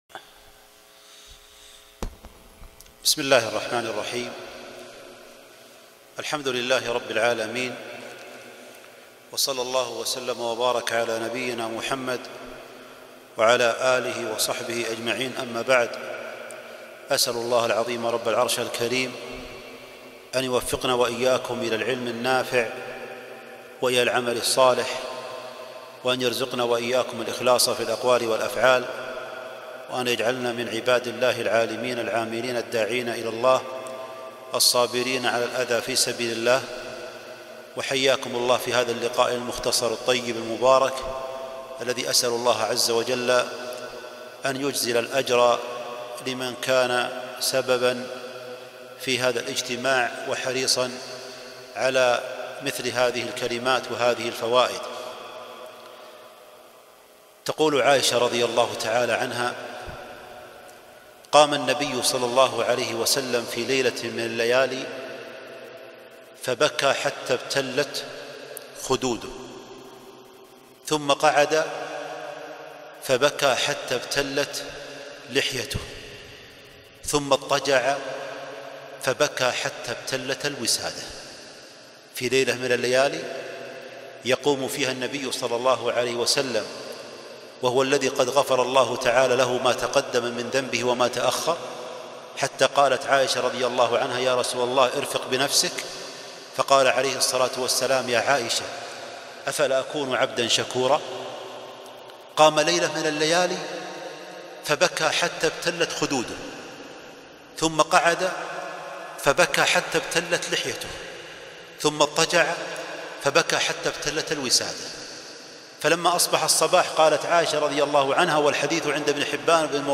دلائل التوحيد - محاضرة رائعة